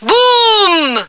flak_m/sounds/female2/est/F2boom.ogg at 9e43bf8b8b72e4d1bdb10b178f911b1f5fce2398